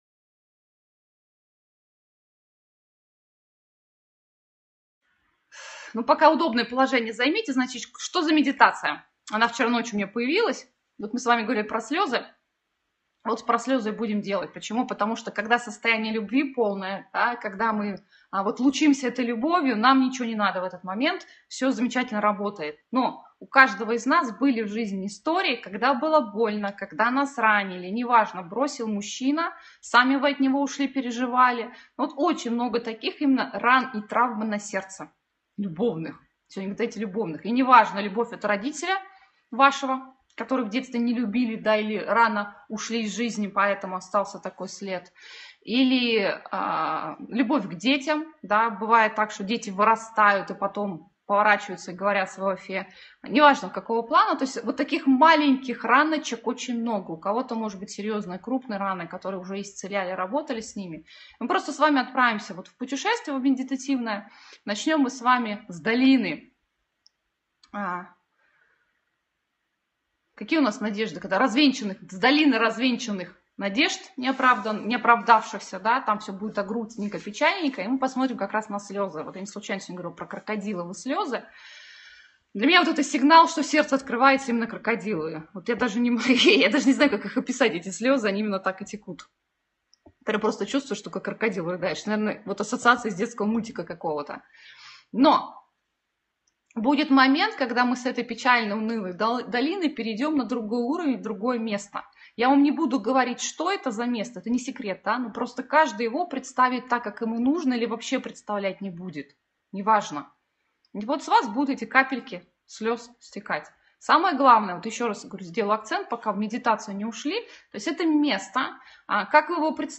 Медитация Исцеление ран Любви
meditacziya-isczelenie-ran-lyubvi.mp3